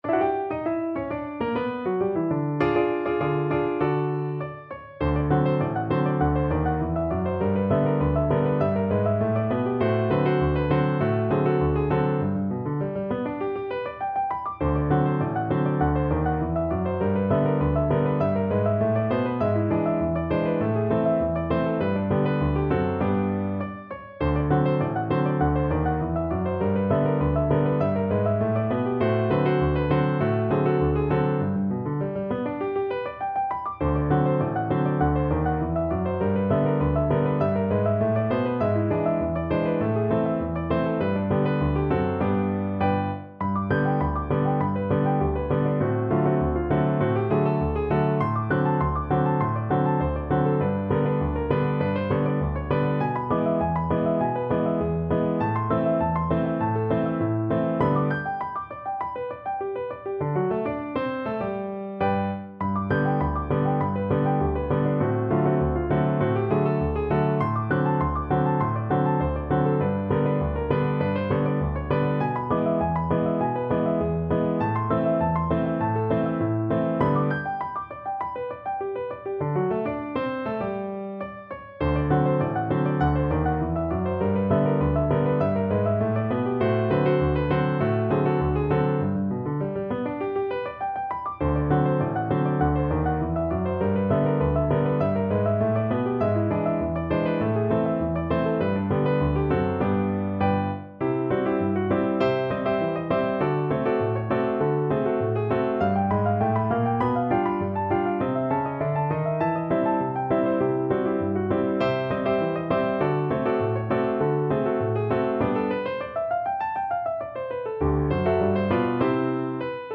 No parts available for this pieces as it is for solo piano.
Moderato
2/4 (View more 2/4 Music)
Piano  (View more Advanced Piano Music)